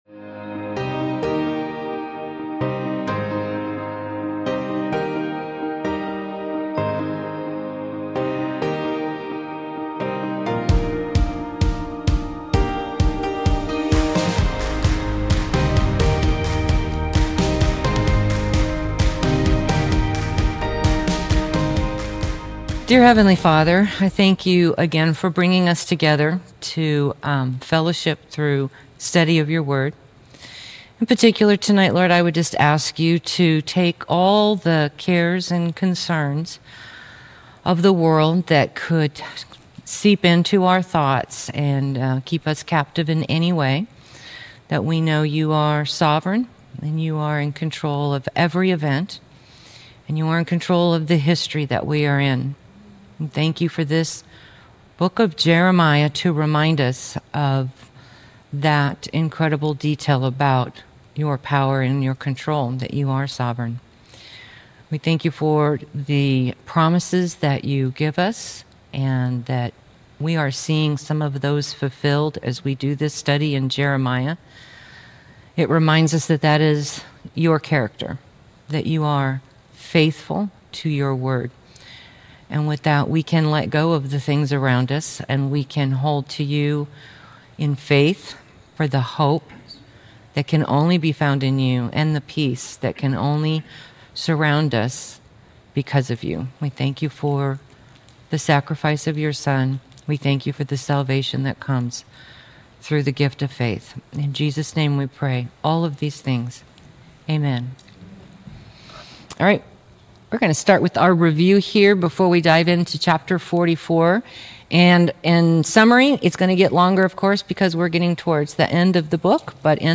Jeremiah - Lesson 44 | Verse By Verse Ministry International